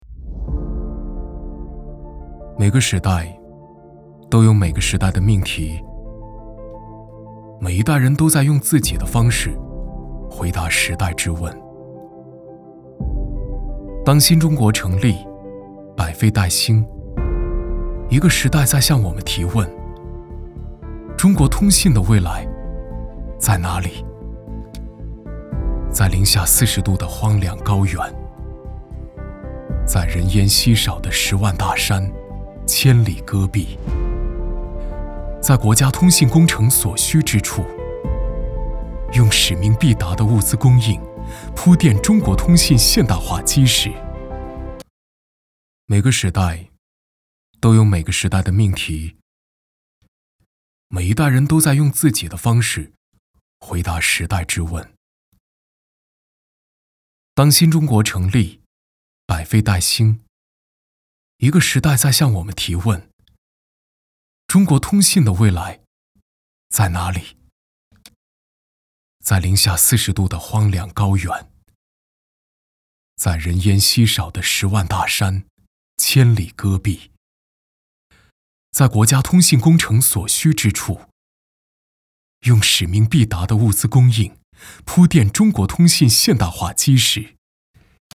男配音-配音样音免费在线试听-第63页-深度配音网